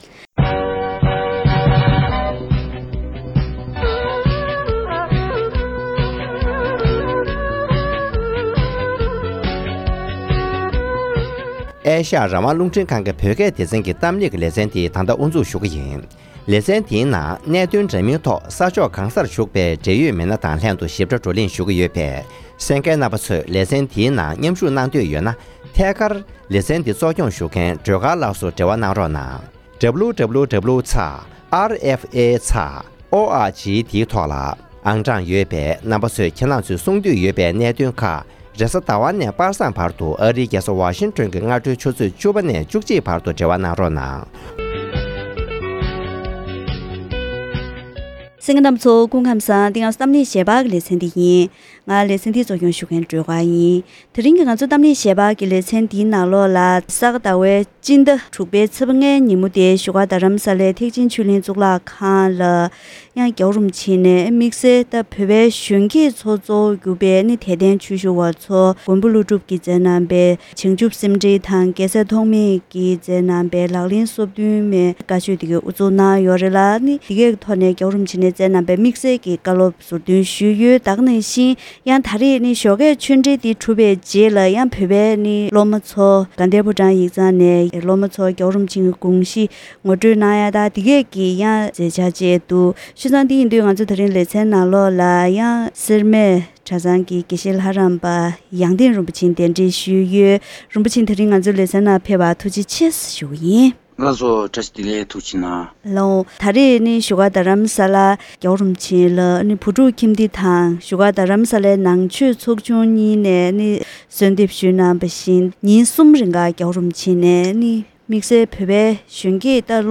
ཐེངས་འདིའི་གཏམ་གླེང་ཞལ་པར་ལེ་ཚན་ནང་།